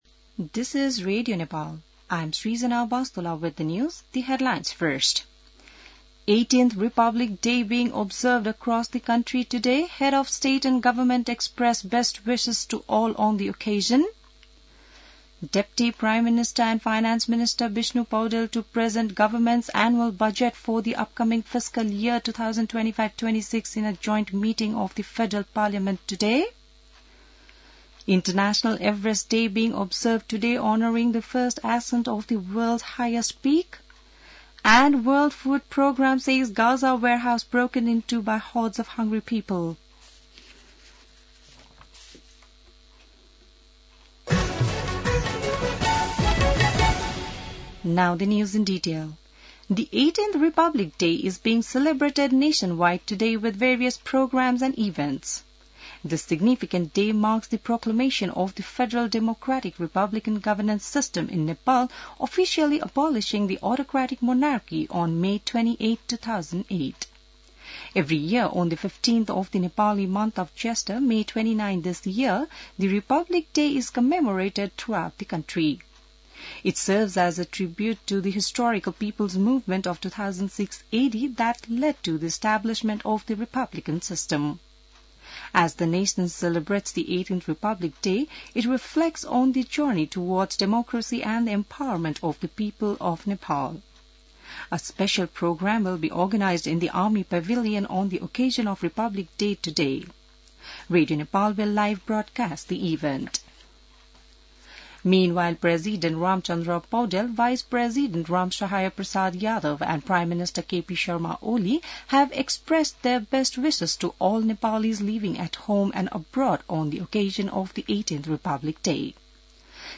बिहान ८ बजेको अङ्ग्रेजी समाचार : १५ जेठ , २०८२